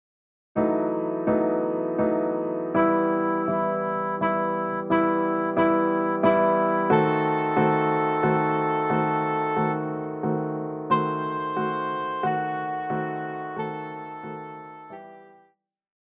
Classical
Vocal - female,Vocal - male
Piano
Instrumental
Voice with accompaniment
This arrangement is in E minor.